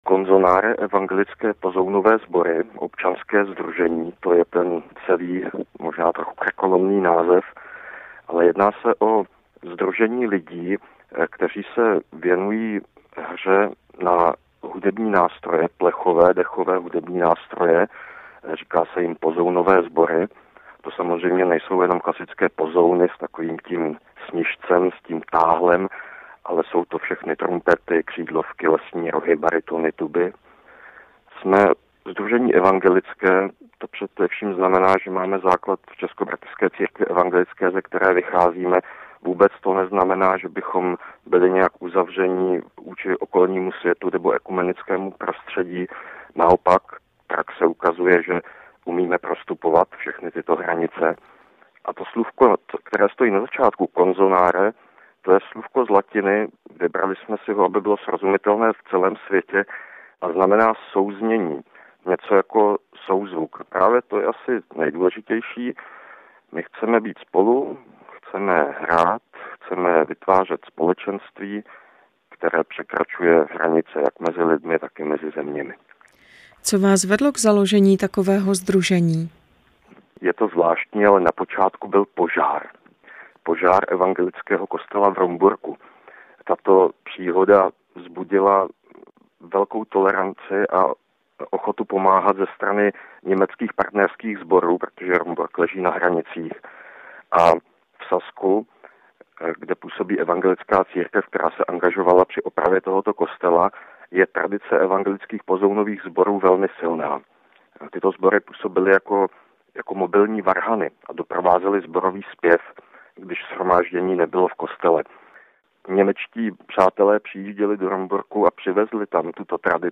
Díky iniciativě Rádia 7 si v přiloženém souboru můžete poslechnout rozhovor, který stručně informuje o sdružení Consonare.
Consonare_rozhovor_pro_Radio_7.mp3